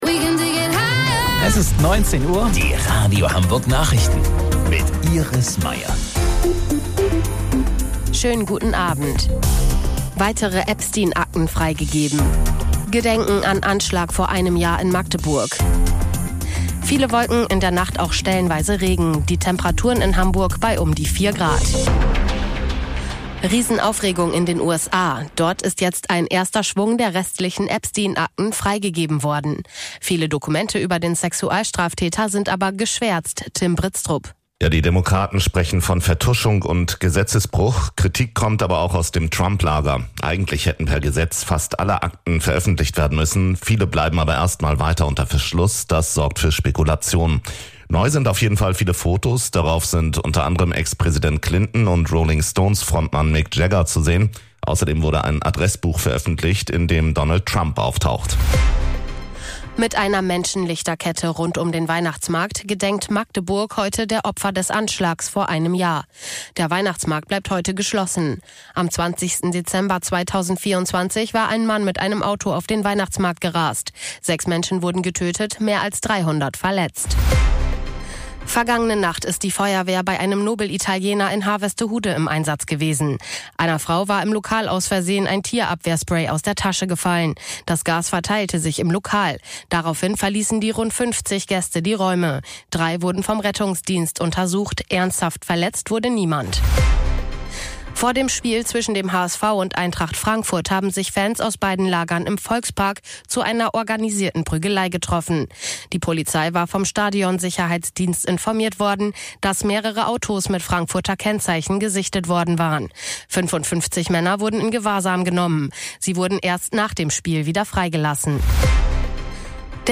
Radio Hamburg Nachrichten vom 20.12.2025 um 19 Uhr